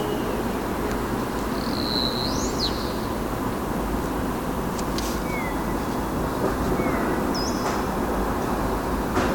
Observação BirdNET - Estorninho-preto - 2022-04-20 09:19:04
Estorninho-preto observado com o BirdNET app. 2022-04-20 09:19:04 em Lisboa